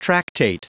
Prononciation du mot tractate en anglais (fichier audio)
Prononciation du mot : tractate